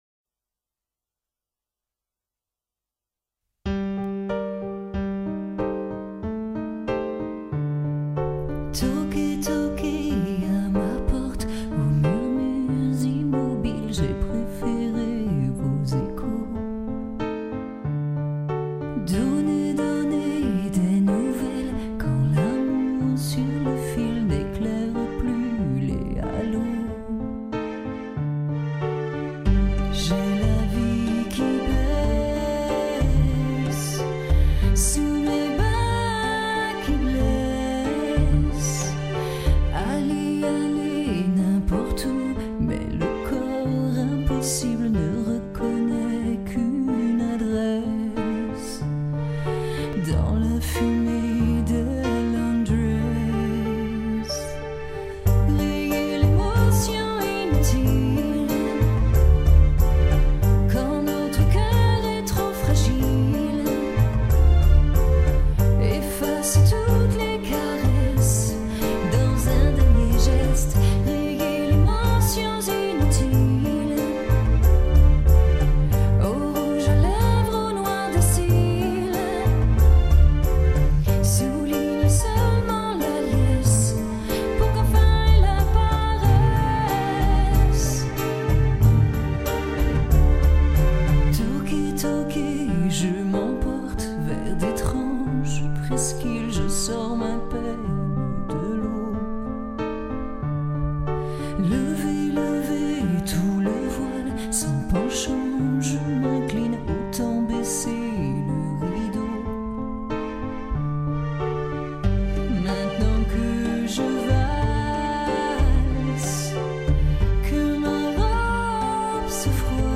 chanson
- Mezzo-soprano